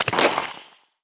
Wep Bubblegun Hitplayer Sound Effect
wep-bubblegun-hitplayer.mp3